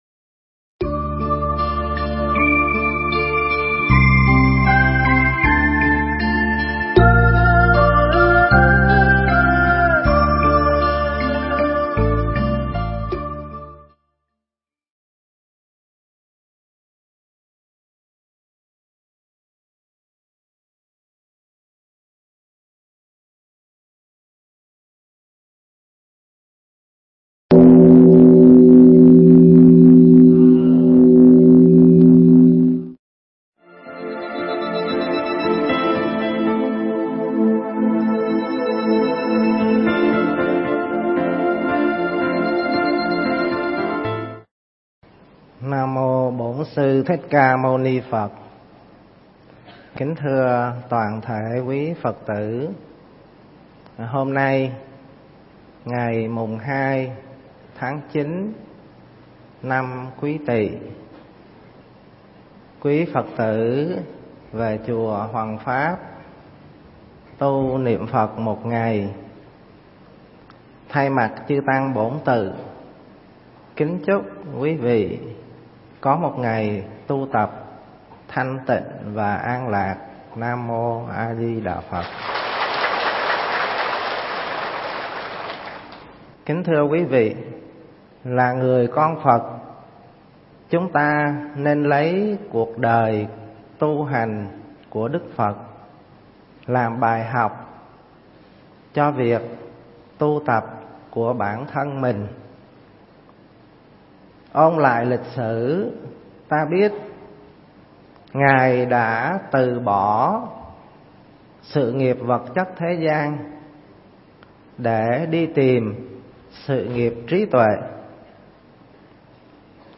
Nghe Mp3 thuyết pháp Tìm Chốn Tu Thân